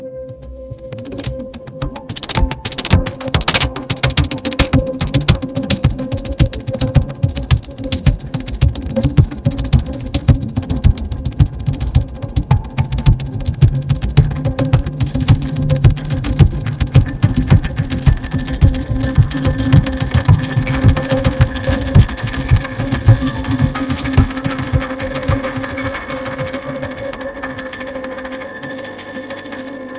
テキストから音楽に
ポップでエレクトロニクスな感じで、女性が歌ってそうな曲、曲調に変化を付けてください。